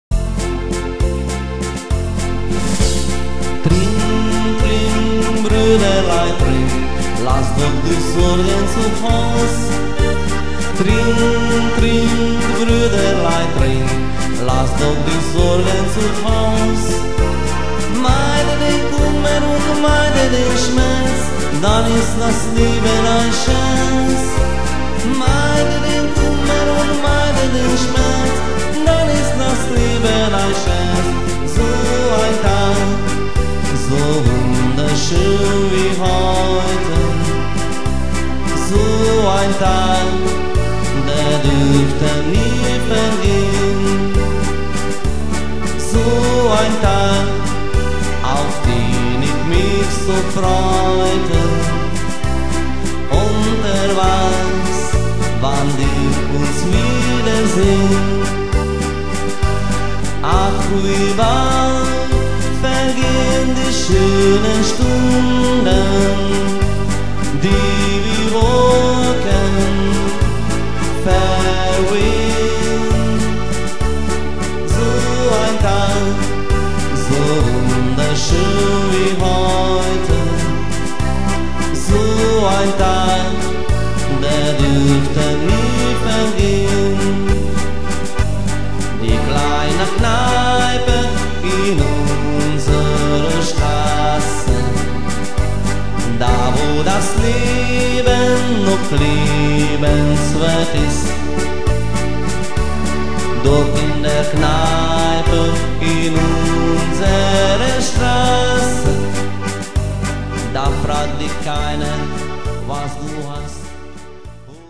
Programm mit meinem Gesang